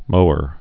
(mōər, mə-hr)